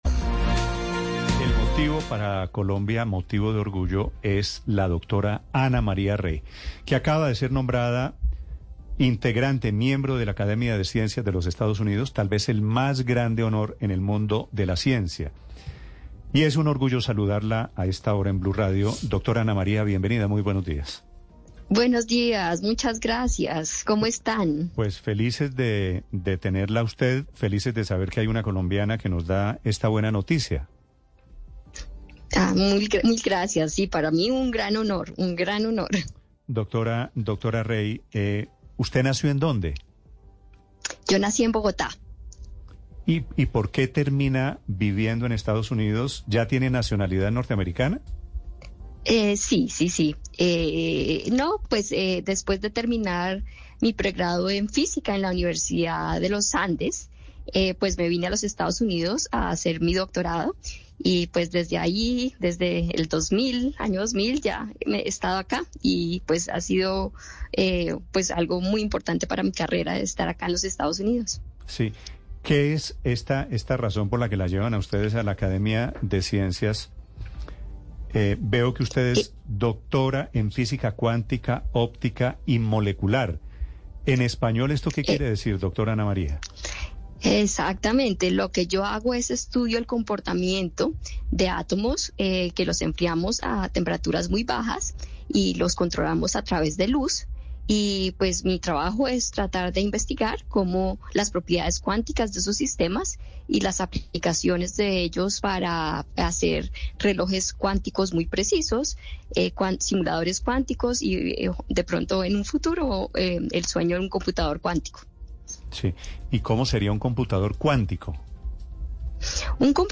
En diálogo con Mañanas Blu, con Néstor Morales, Ana María Rey, contó cuál ha sido su trayectoria y cómo llegó a ser parte de la academia.